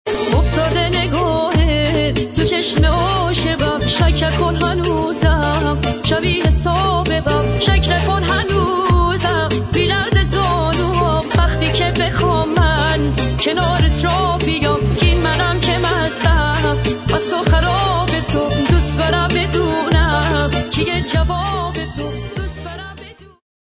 ساخته شده با هوش مصنوعی